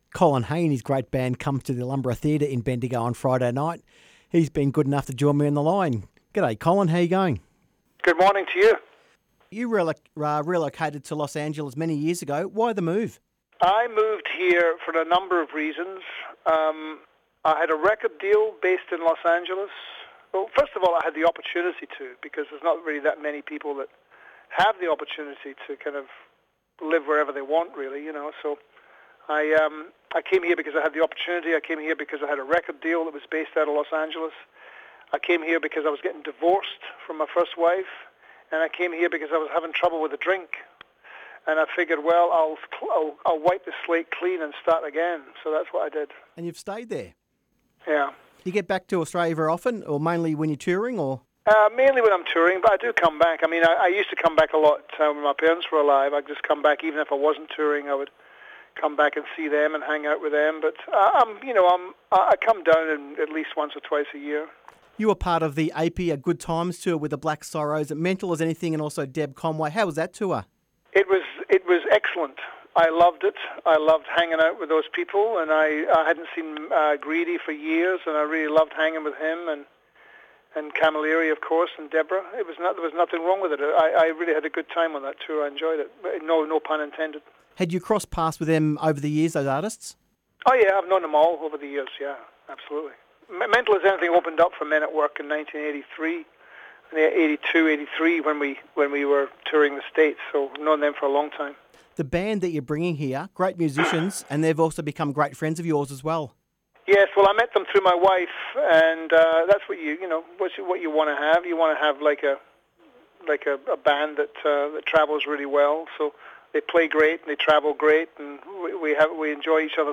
Colin Hay interview.